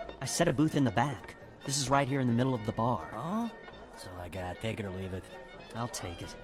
Dark Forces: Rebel Agent audio drama